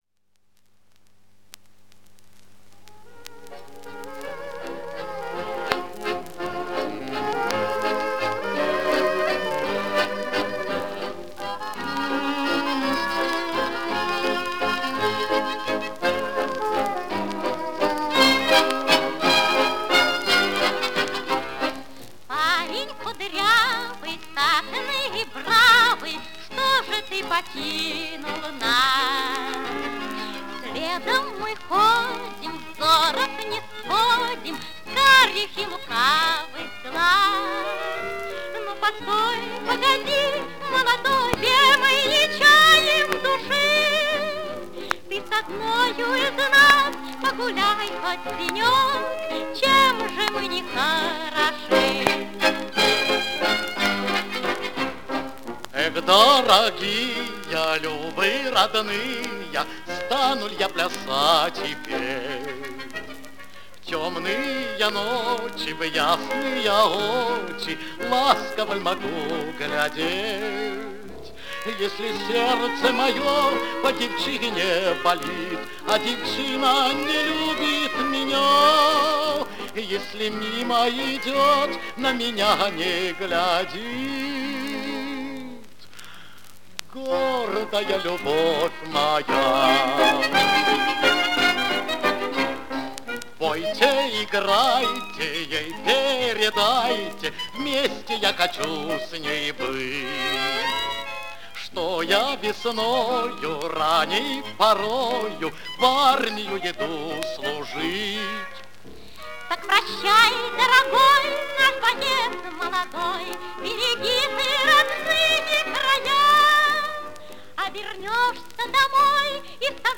Прекрасный образец любвно-солдатской лирики 30-х годов